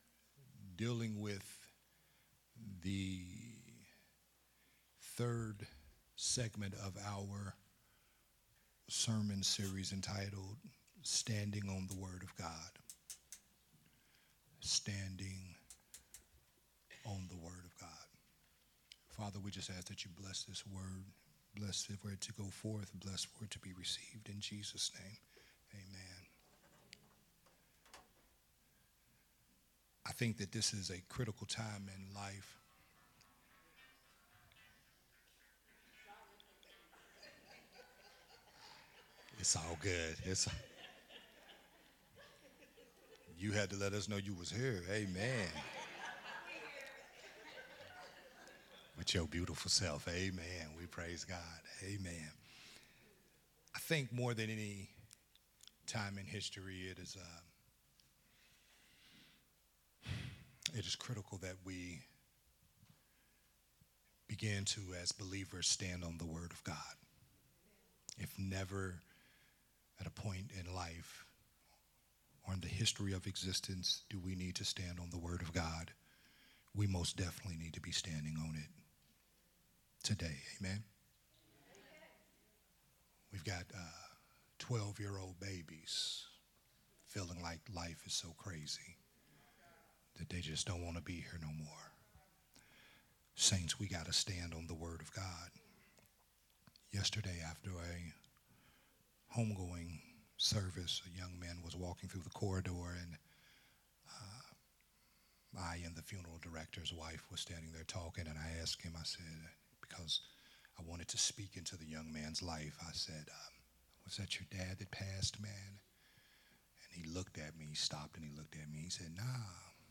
Part 3 of the sermon series
recorded at Unity Worship Center